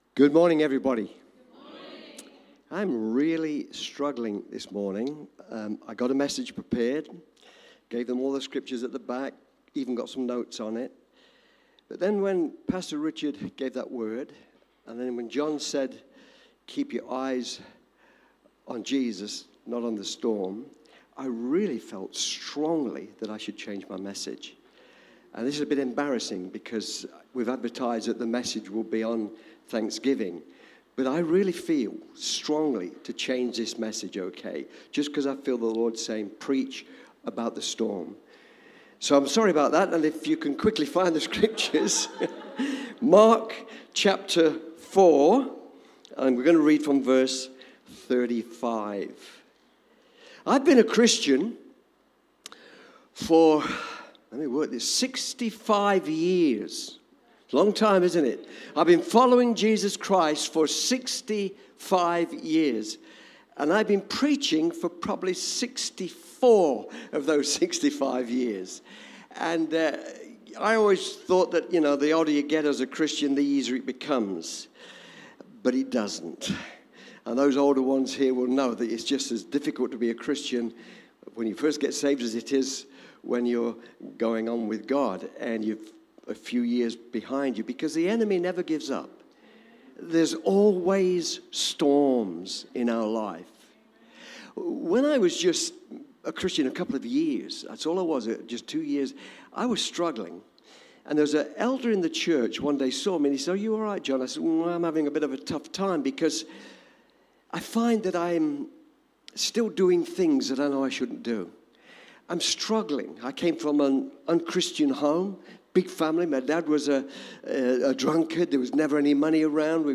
Cityview-Church-Sunday-Service-Thankful-Attitude.mp3